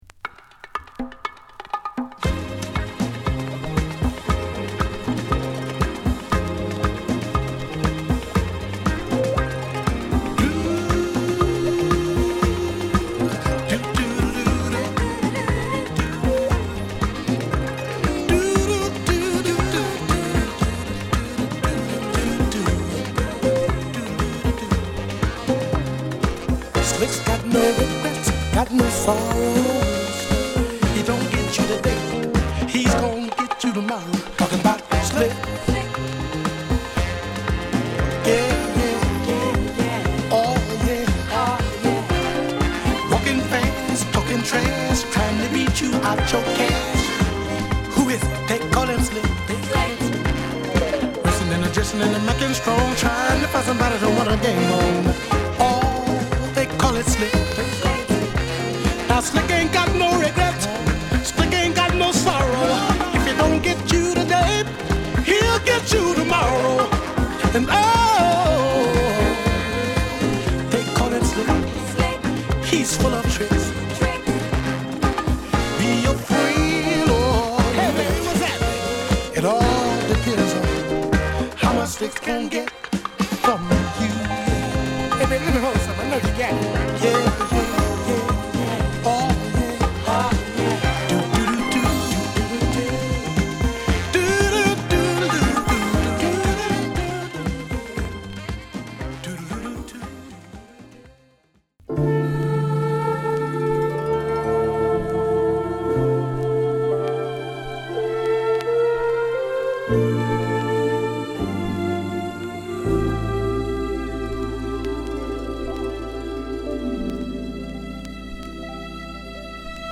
小気味良いパーカッションやドラムにサントラらしいスリリングなホーンやストリングスが絡む